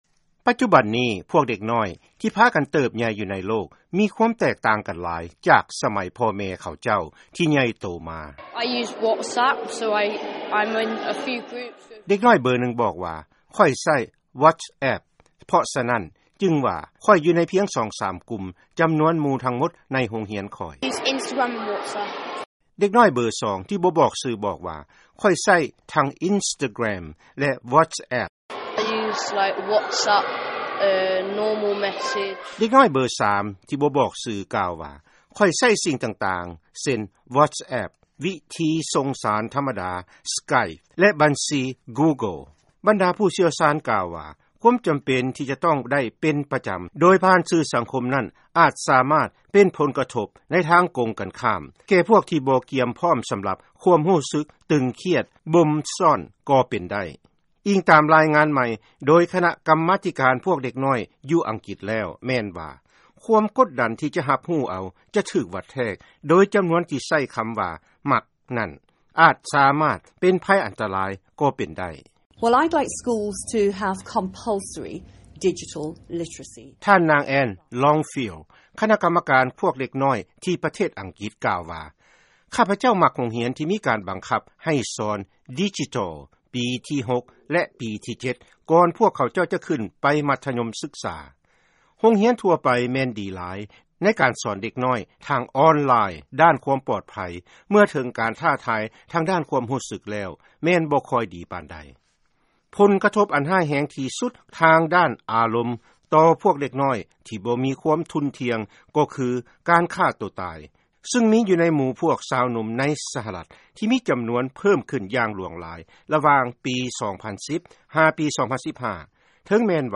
ເຊີນຟັງລາຍງານ ໄພອັນຕະລາຍ ການໃຊ້ສື່ສັງຄົມ ແກ່ສຸຂະພາບພວກເຍົາໄວ.